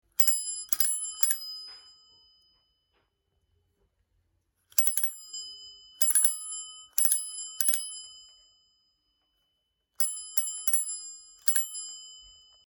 Разные велосипедные звуки: колеса и трещотки велосипедные, езда на велосипеде, звонок, тормоза, цепи.
6. Тот самый звонок много раз звонят
zvonok-velosipeda-4.mp3